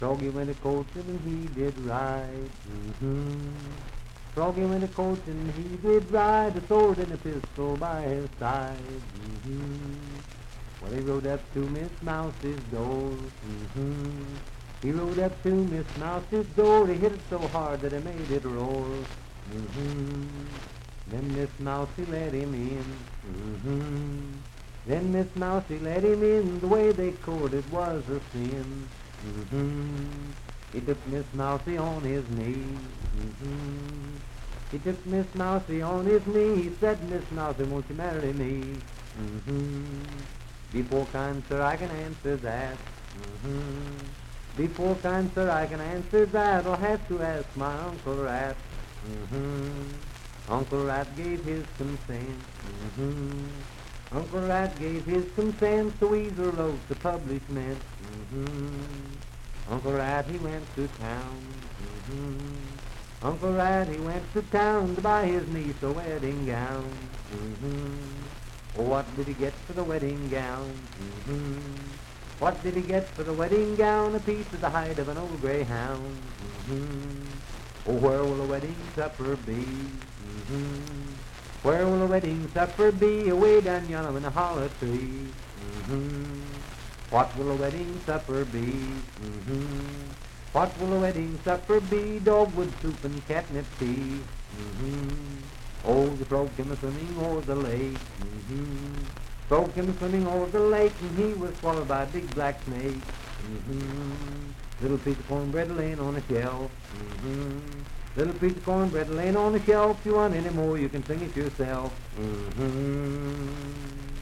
Unaccompanied vocal music
Children's Songs, Dance, Game, and Party Songs
Voice (sung)
Parkersburg (W. Va.), Wood County (W. Va.)